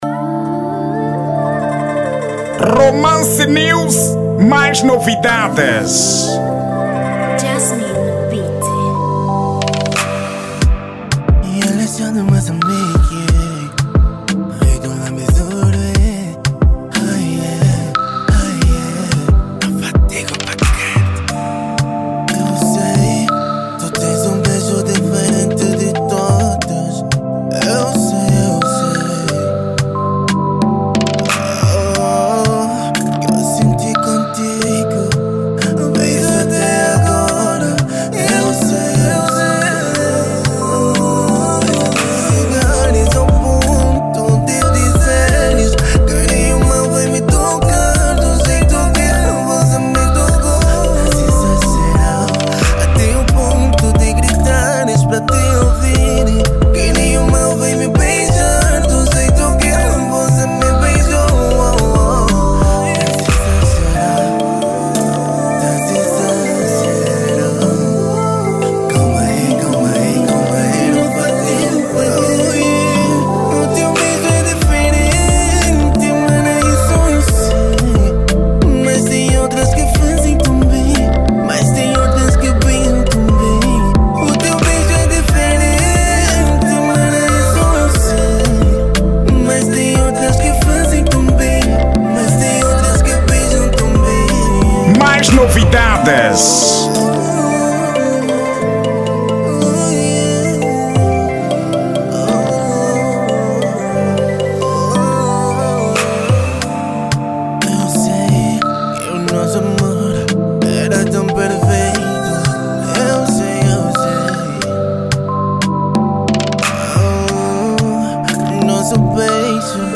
Estilo: ZouK